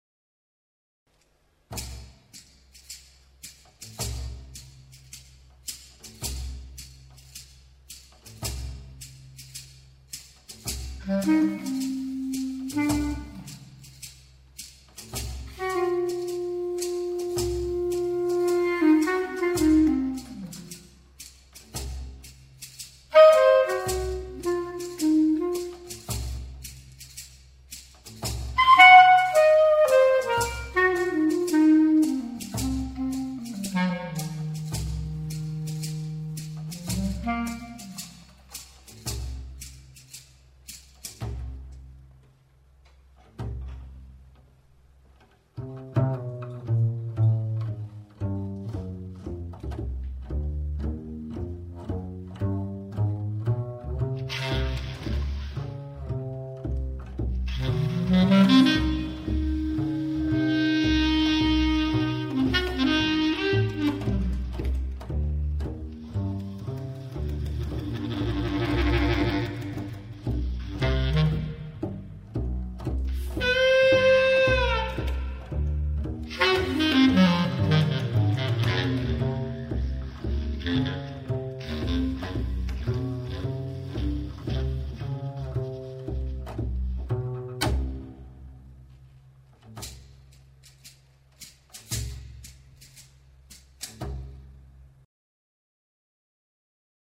Livemusik zu Stummfilmen
Kontrabass, Melodica und Percussion
Saxophone, Klarinetten, Flöten